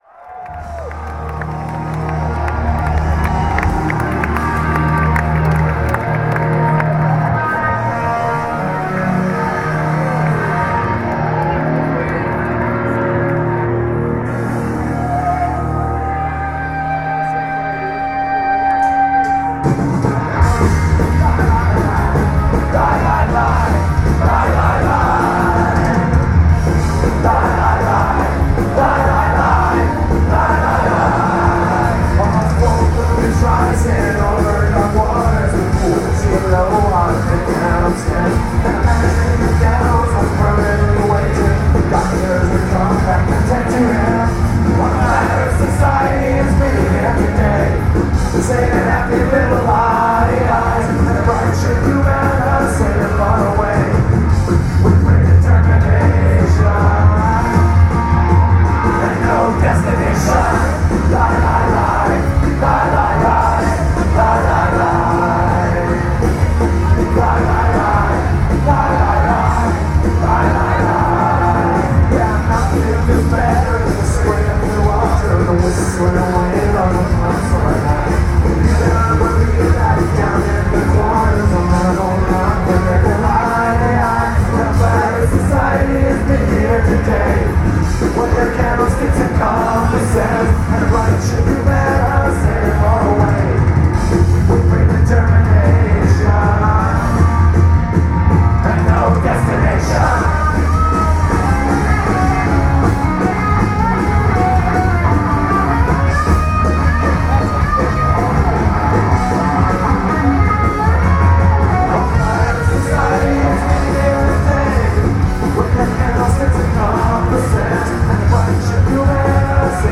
the California pop-punk band
and played three special New York only shows